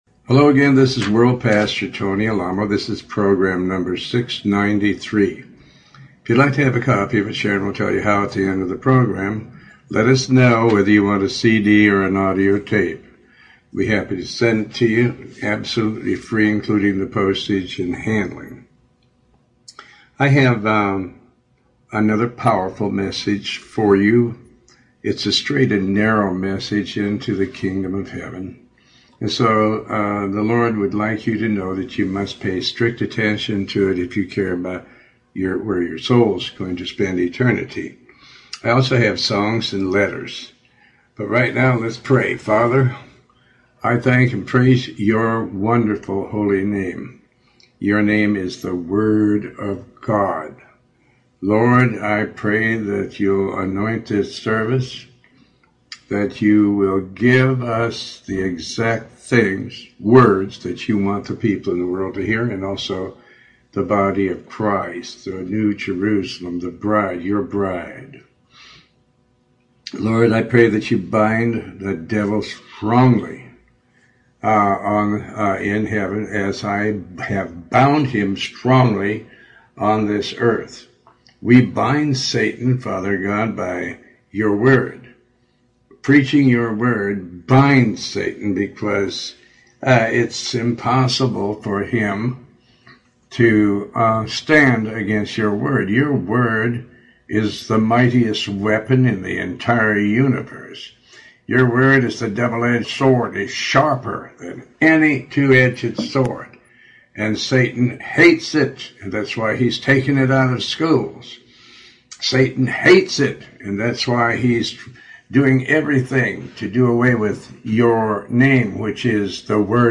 Show Host Pastor Tony Alamo